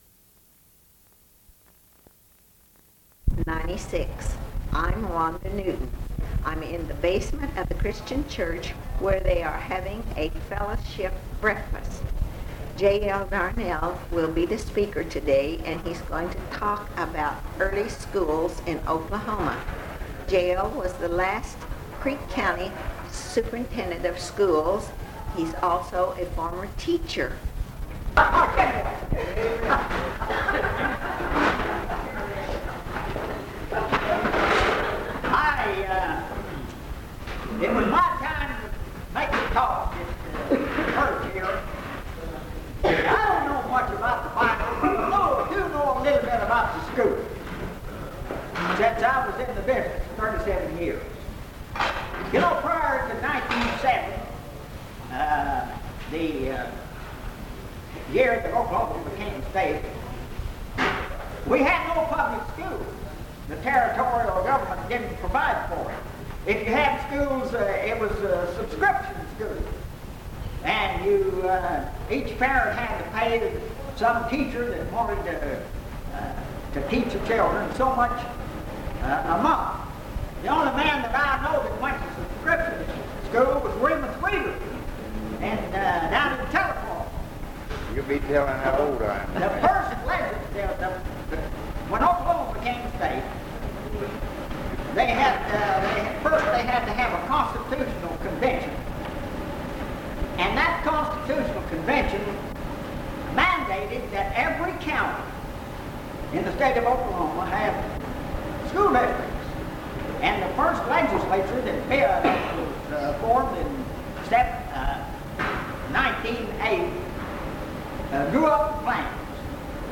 Bristow Historical Society - Oral History Archive | Lectures